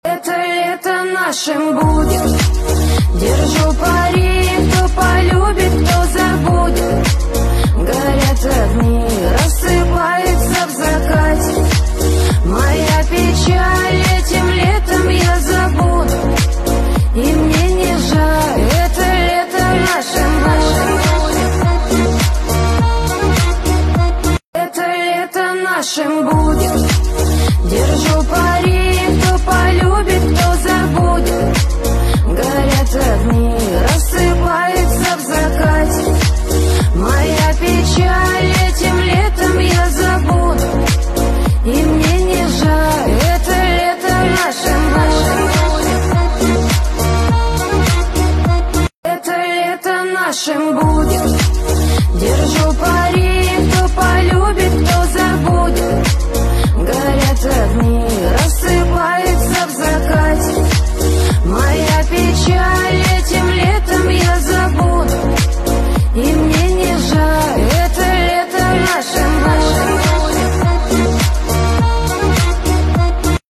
Жанр: Казахские